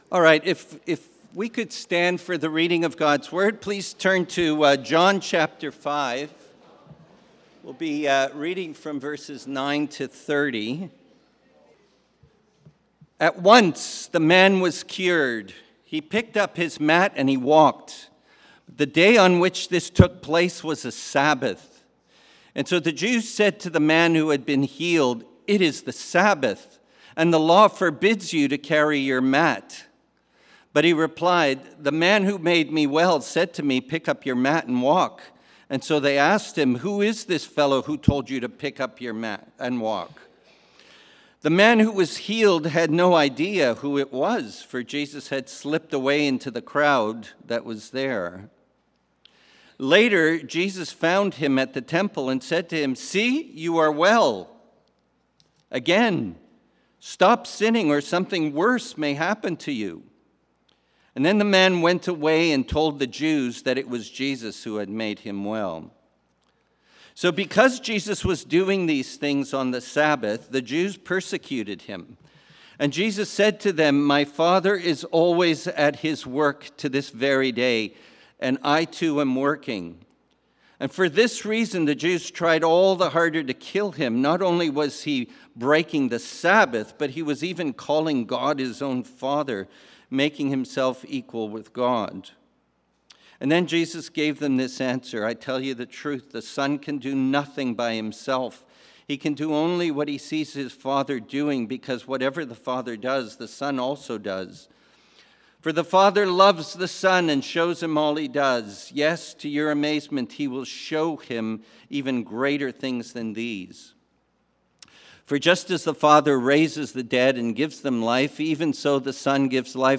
Sermons | Mountainview Christian Fellowship